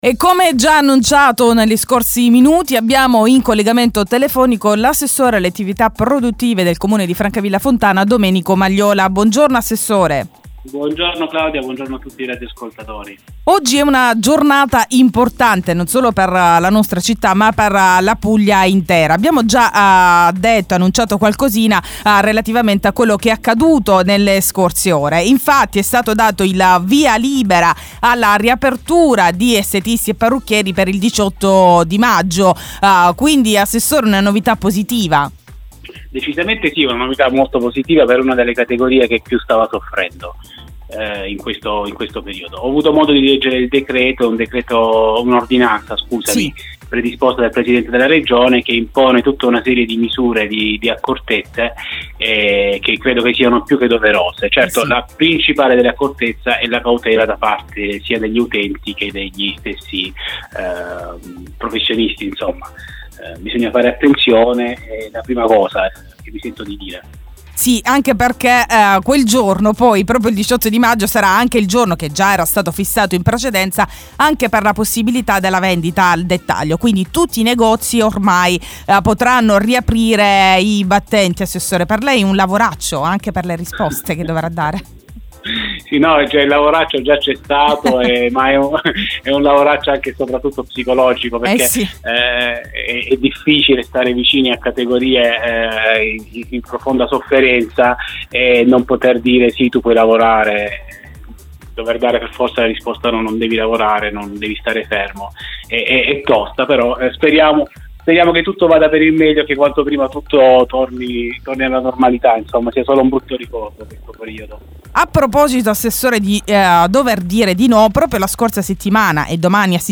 MERCATO SETTIMANALE e RIAPERTURA DI CENTRI ESTETICI E PARRUCCHIERI, ne abbiamo parlato questa mattina con l'assessore Domenico Magliola